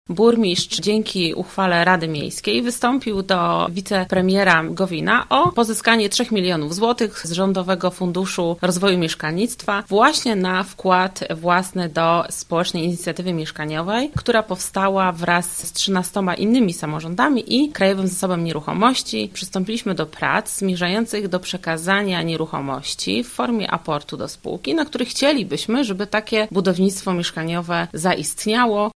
’- Inicjatywie osobiście patronuje wicepremier Jarosław Gowin, do którego wystąpiliśmy o wsparcie tej inicjatywy – tłumaczy Adriana Dydyna – Marycka, zastępca burmistrza Słubic.